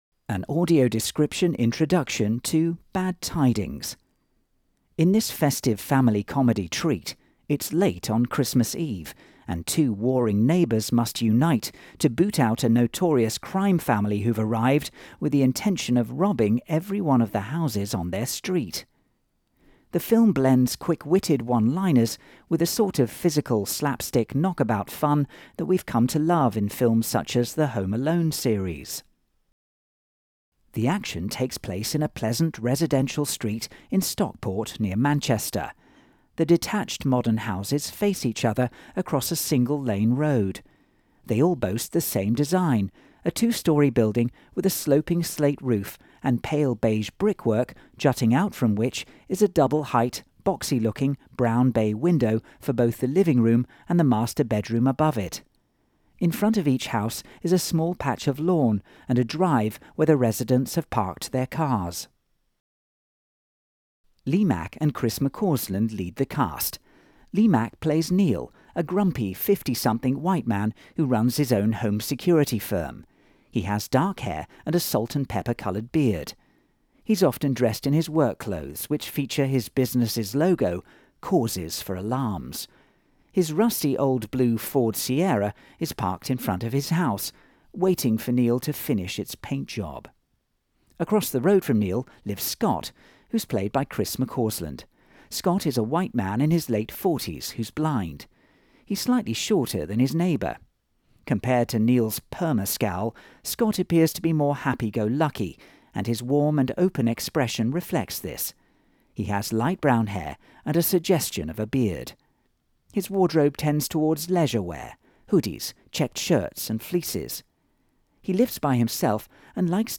Bad Tidings AD Introduction
Bad_Tidings_AD_Introduction.wav